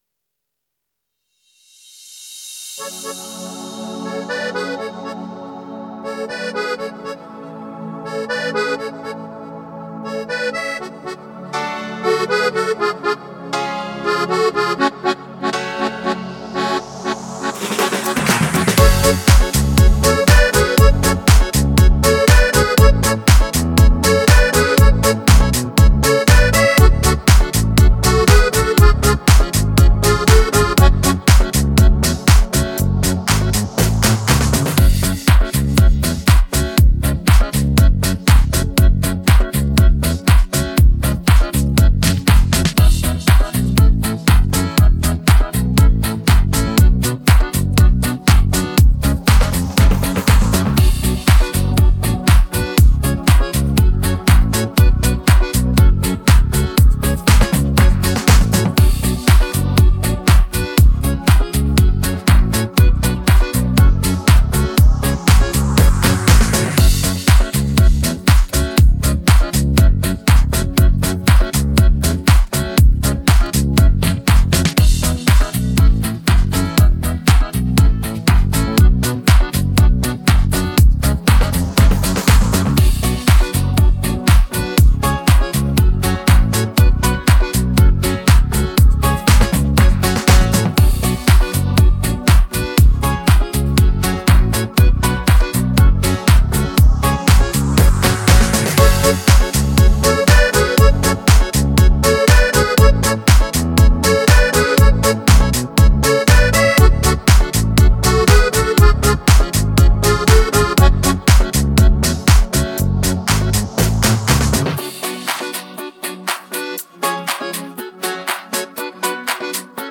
• Качество: Хорошее
• Категория: Детские песни
Слушать минус
минусовка